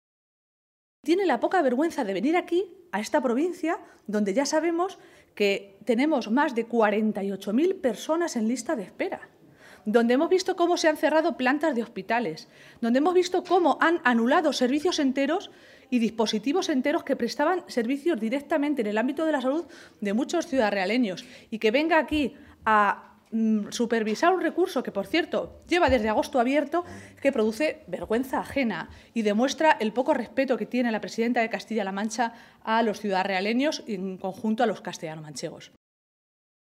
Maestre se pronunciaba de esta manera esta tarde, en Ciudad Real, minutos antes de que comenzara la reunión de la ejecutiva regional socialista, presidida por García-Page en esa ciudad manchega.